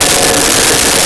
Nuclear Scanner
Nuclear Scanner.wav